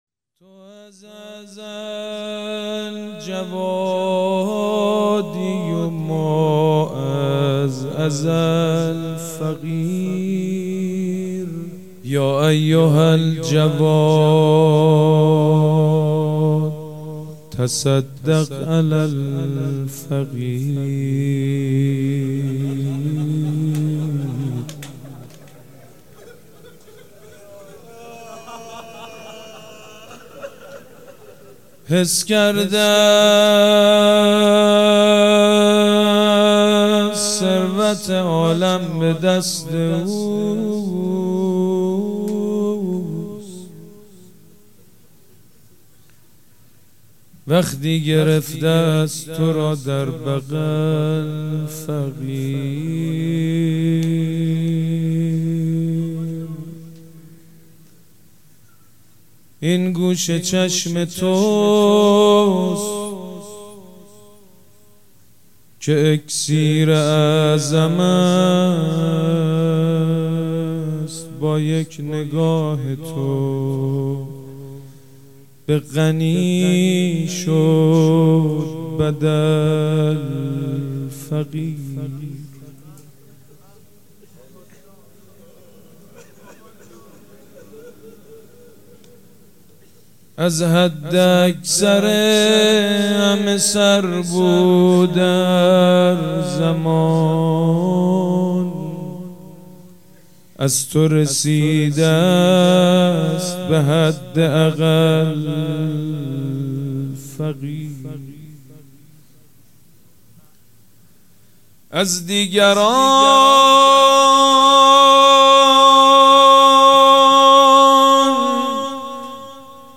روضه خوانی شهادت امام جواد علیه السلام با نوای سید مجید بنی فاطمه و شعری از مجتبی خرسندی در هیئت ریحانة الحسین تهران.